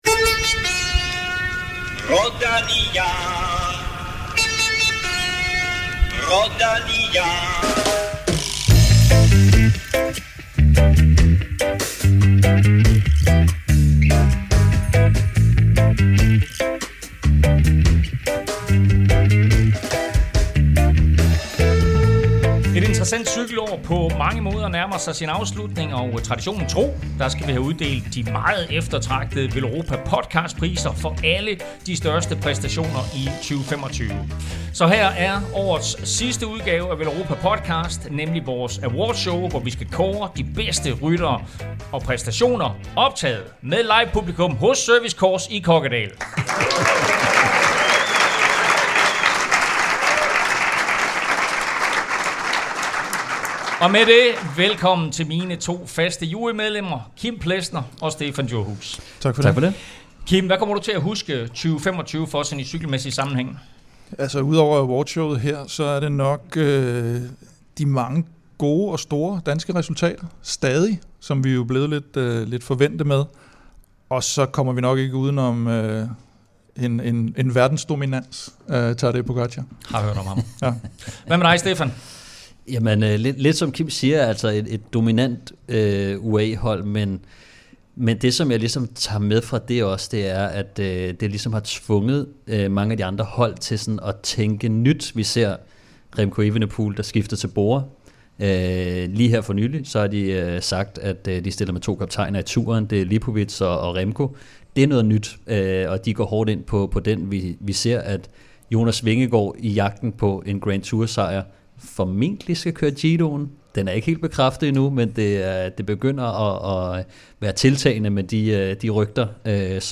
Traditionen tro skal vi have kåret årets bedste præstationer internationalt og blandt de danske ryttere. Foran et veloplagt live-publikum med mere end 100 trofaste 10'er støtter hos Service Course i Kokkedal, blev det afsløret hvem der fik de ekstremt prestigiøse priser i 2025...